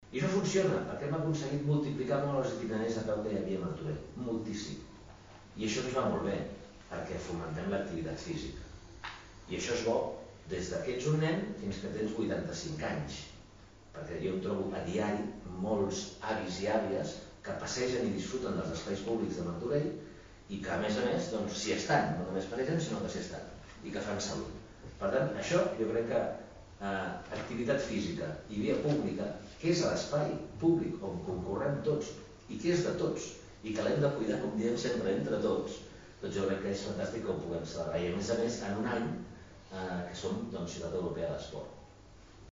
Aquest dimecres, s’ha dut a terme a la Sala de Plens de l’Ajuntament, la presentació institucional d’aquesta jornada esportiva, que se celebrarà el pròxim 26 d’abril.